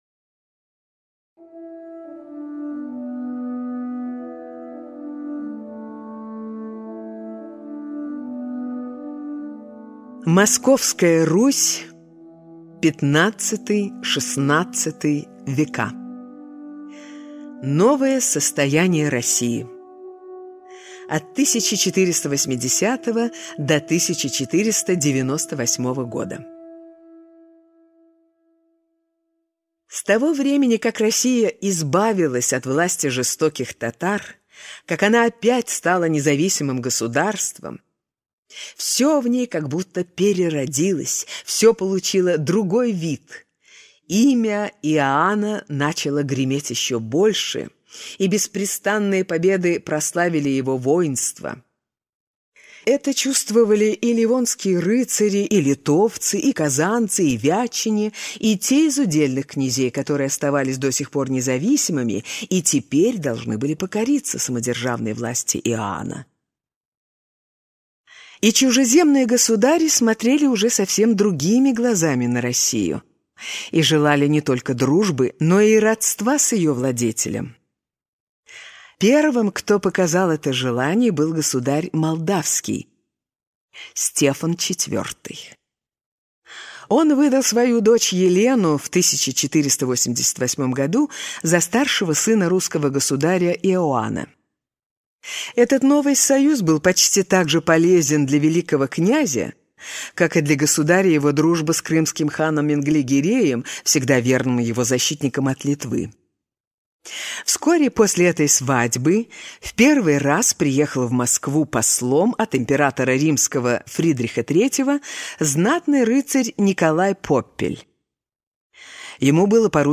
Аудиокнига История России в рассказах для детей. Выпуск 2 | Библиотека аудиокниг